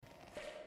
HeadInflatePOP 2.0.mp3